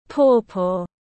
Pawpaw /ˈpɔː.pɔː/